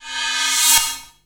VEC3 Reverse FX
VEC3 FX Reverse 32.wav